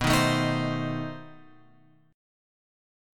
B 7th Flat 9th